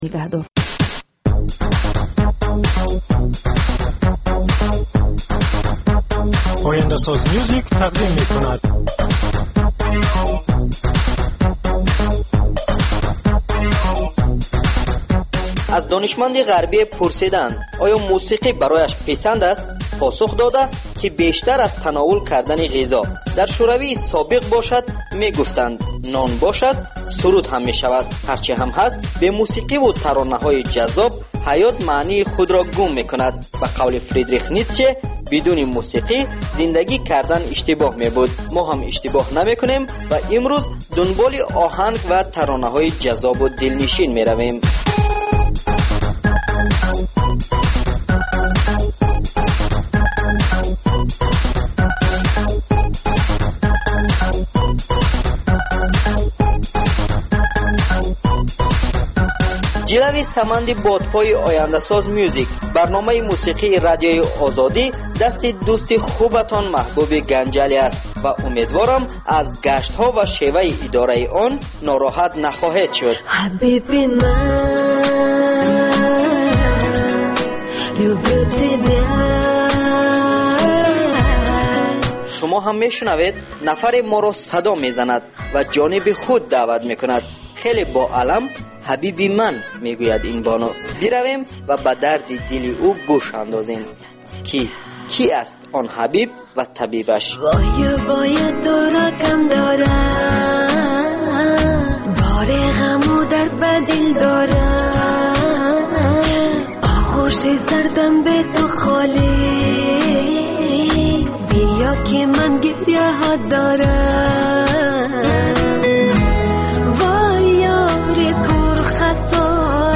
Барномаи мусиқӣ
Навгониҳои мусиқӣ, беҳтарин оҳангҳо, гуфтугӯ бо оҳангсозон, овозхонон ва бинандагон, гузориш аз консертҳо ва маҳфилҳои ҳунарӣ.